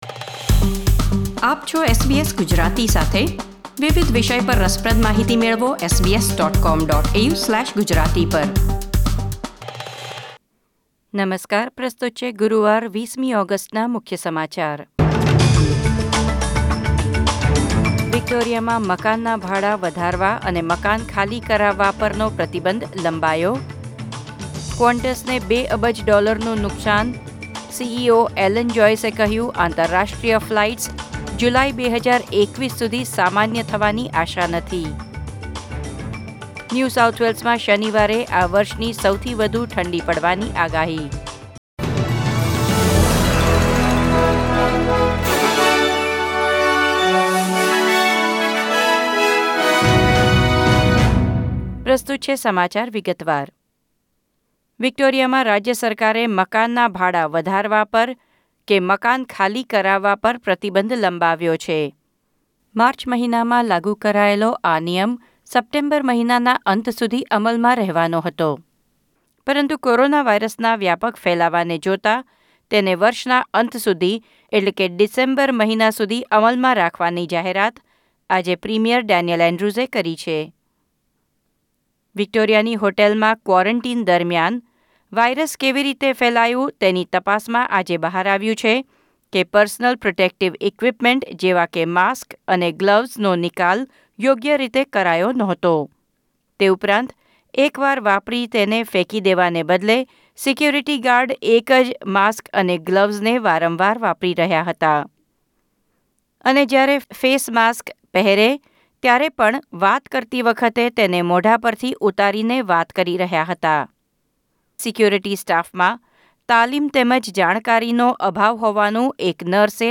SBS Gujarati News Bulletin 20 August 2020